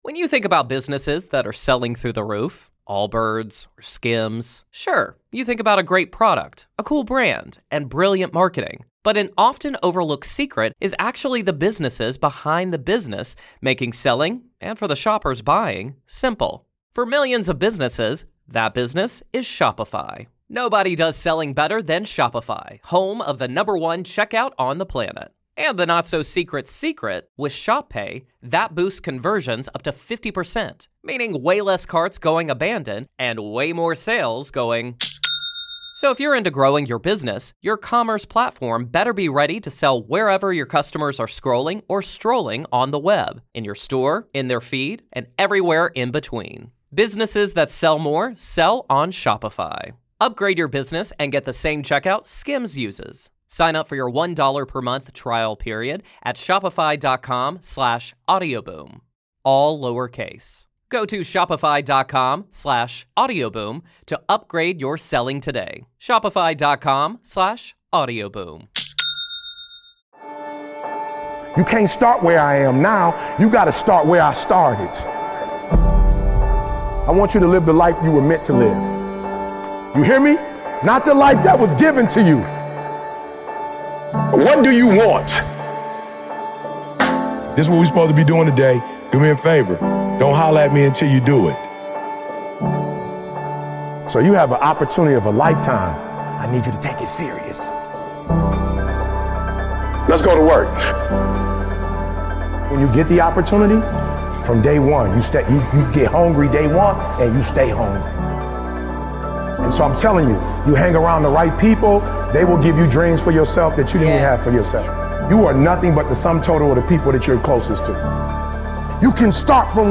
Put your head down and work. It is one of the BEST Motivational Speeches of all time featuring Eric Thomas.